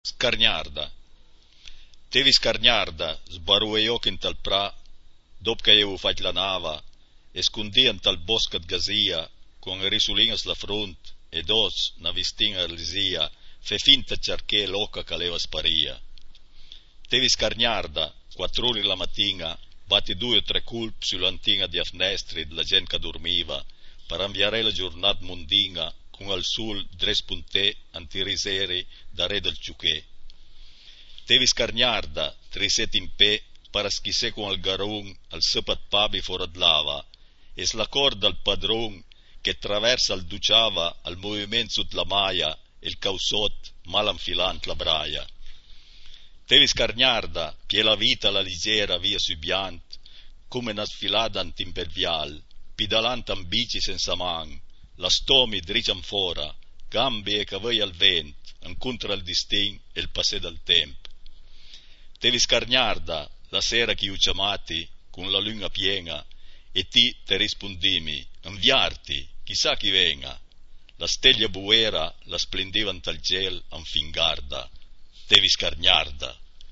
cliché chi par sénti la puizìa recità da l'autùr